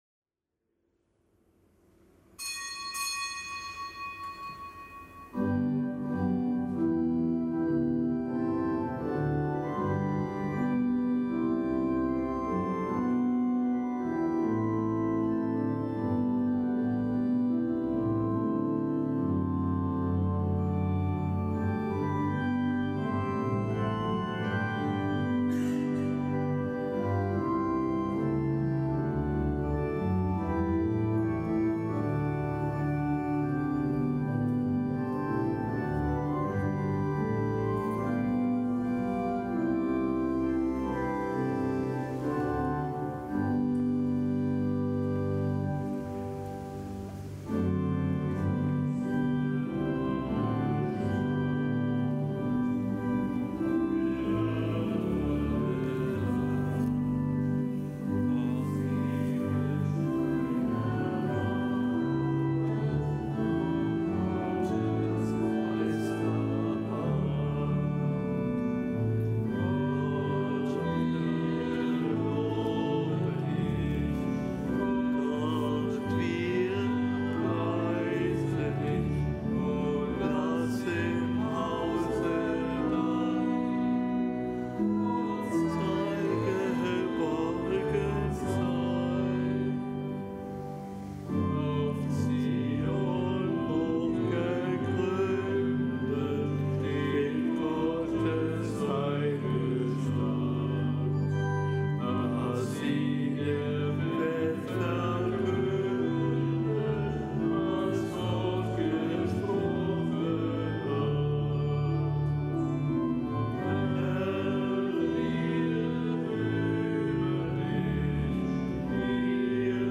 Kapitelsmesse aus dem Kölner Dom am Gedenktag des Heiligen Georg des Großen. Zelebrant: Weihbischof Rolf Steinhäuser.